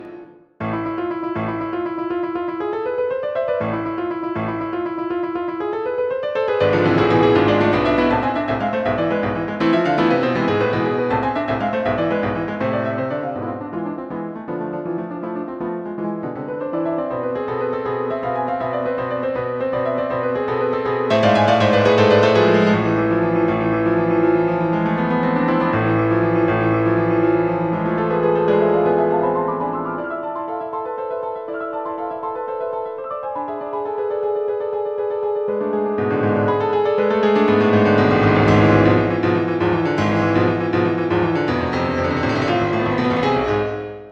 Op.31 - Piano Music, Solo Keyboard - Young Composers Music Forum